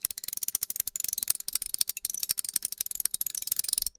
Clockwork Ticking
Precise clockwork mechanism ticking with escapement clicks and subtle spring tension
clockwork-ticking.mp3